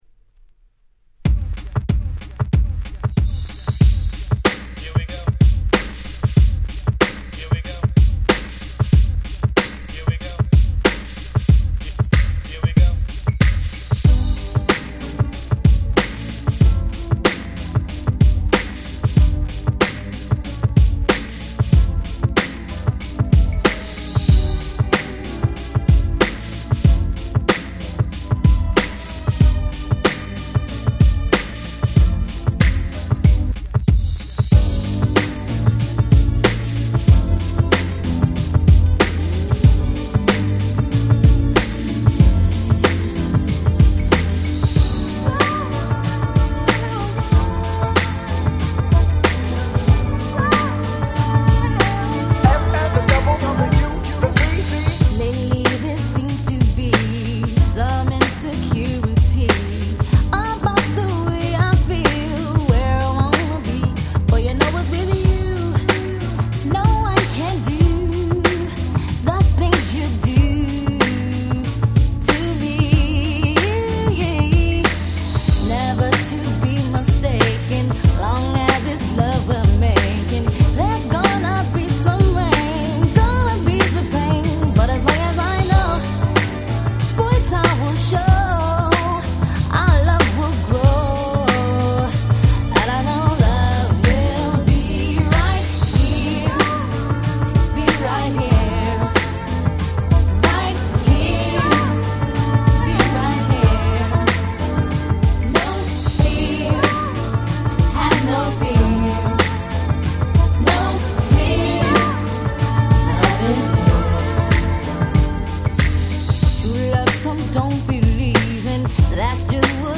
93' Super Hit R&B !!